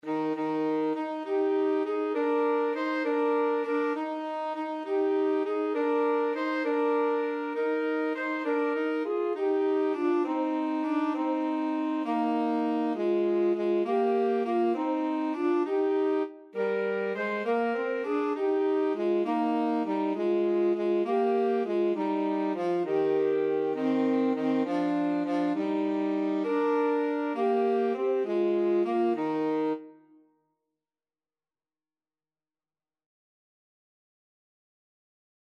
Alto SaxophoneTenor Saxophone
6/8 (View more 6/8 Music)
Classical (View more Classical Alto-Tenor-Sax Duet Music)